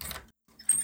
-Добавлены звуки открытия и закрытия двери 2025-09-17 00:20:36 +03:00 72 KiB (Stored with Git LFS) Raw History
open-door.wav